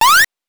8 bits Elements
jump_7.wav